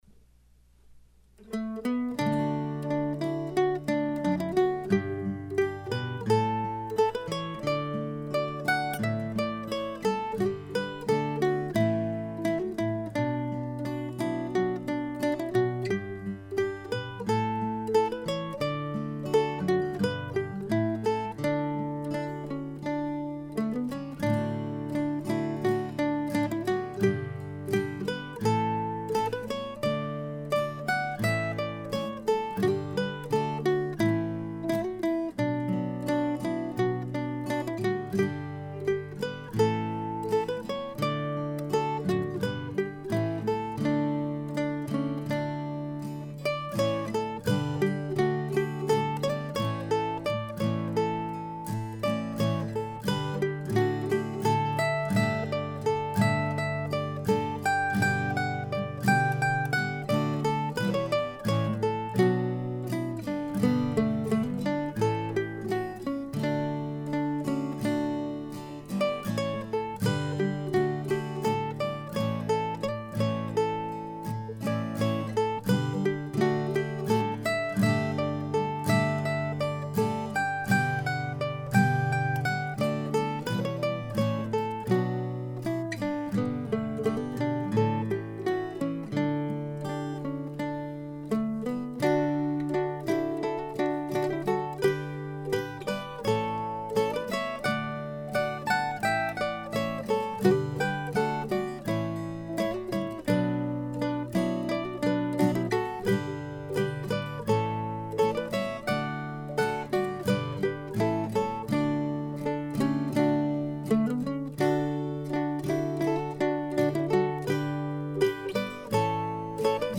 Here's a pretty tune, an air titled after the lovely gardens you will find in front of the Ingraham House at the King's Landing historical settlement near Fredericton, New Brunswick.
It took me a while to settle on the G (add 9) chord in the B section. I'm playing it G-B-D-A(2nd fret G string)-D-G. You're welcome to improve on that.